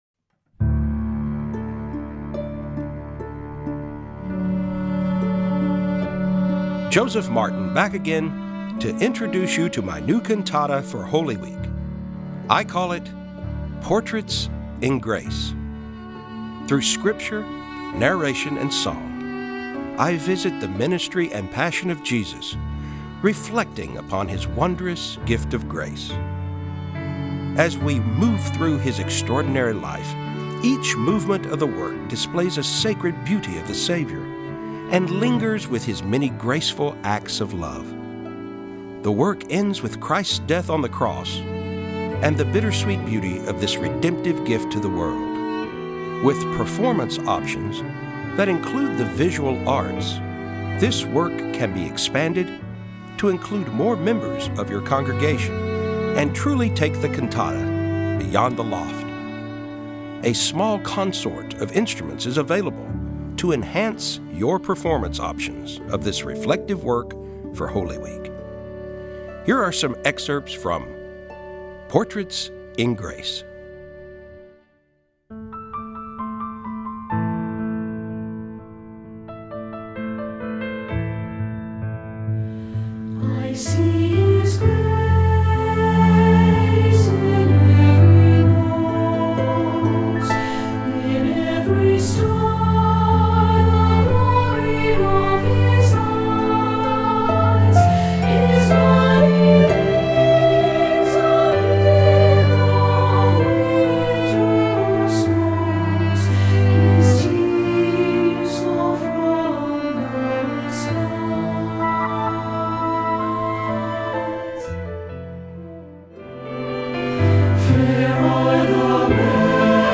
Recueil pour Chant/vocal/choeur - SATB